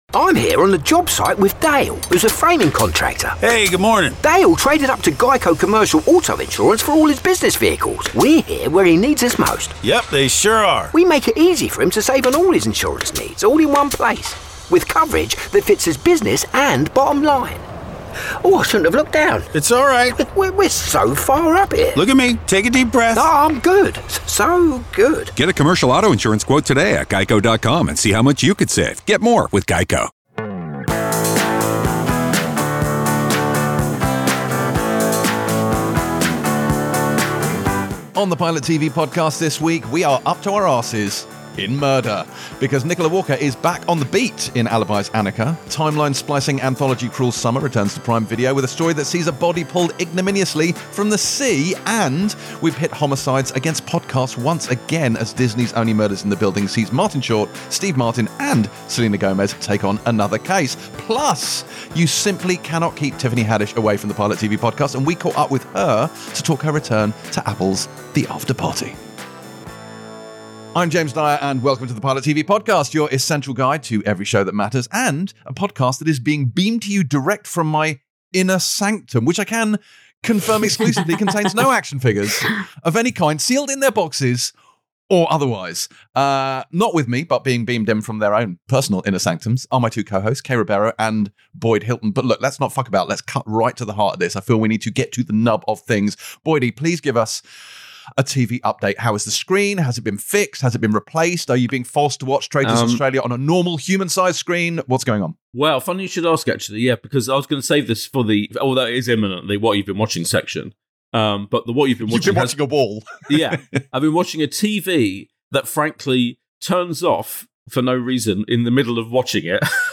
With Guest Tiffany Haddish Pilot TV Empire Magazine Tv & Film, Tv Reviews 4.8 • 1.6K Ratings 🗓 7 August 2023 ⏱ 98 minutes 🔗 Recording | iTunes | RSS 🧾 Download transcript Summary We're celebrating the after-Afterparty this week as Tiffany Haddish joins us (in a pre-strike interview) to chat all things murder as we reach the tail end of Season 2 (don't watch it until you've seen this week's episode, if not, you can skip past it at 52:43). Plus we rejoin Steve Martin, Selena Gomez and Martin Short in the latest pod-sleuthing series of Only Murders in The Building, Nicola Walker is back to addressing us all directly in Annika Series 2, and Cruel Summer returns with an entirely new time-hopping mystery.